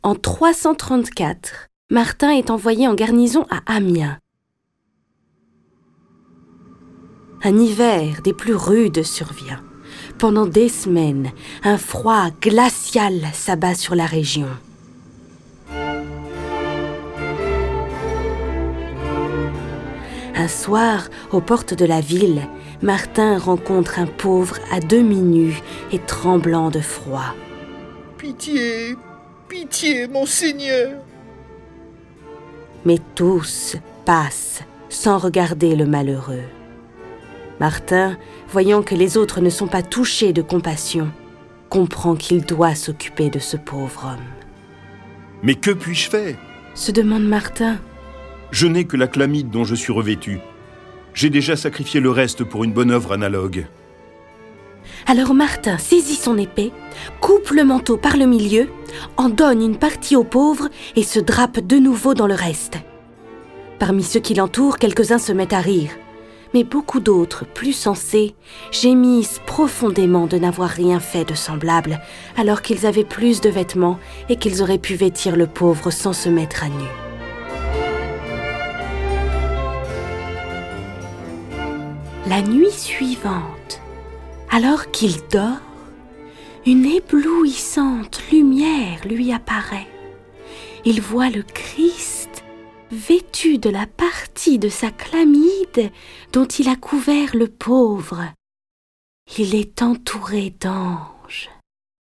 Diffusion distribution ebook et livre audio - Catalogue livres numériques
Le récit et les dialogues sont illustrés avec les musiques de Bach, Charpentier, Delibes, Dvorak, Grieg, Haendel, Haydn, Marcello, Mozart, Pachelbel, Pergolese, Rossini, Tchaïkovski, Telemann, Vivaldi.